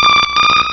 Cri d'Héliatronc dans Pokémon Rubis et Saphir.